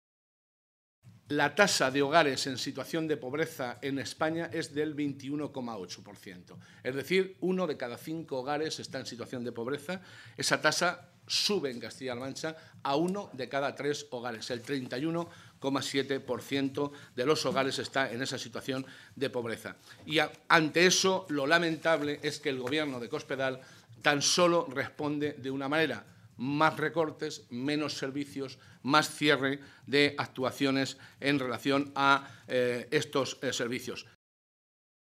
José Molina, coordinador de Economía del Grupo Parlamentario Socialista
Cortes de audio de la rueda de prensa